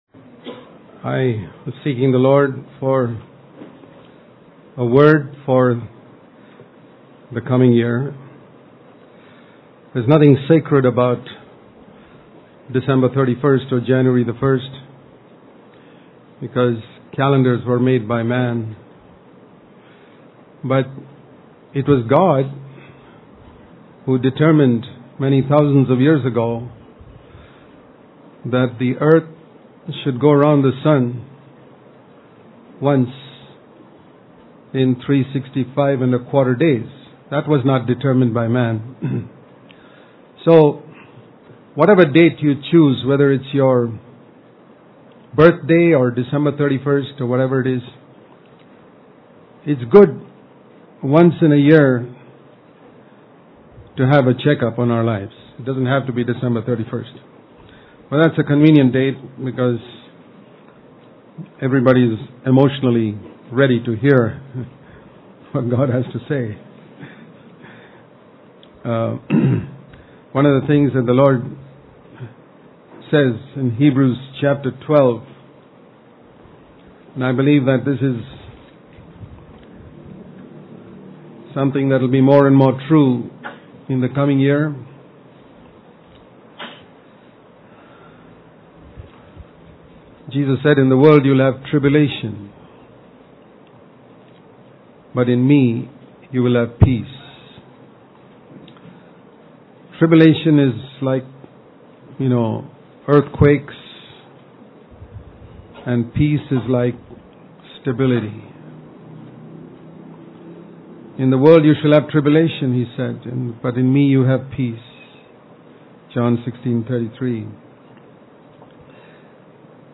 New Year Service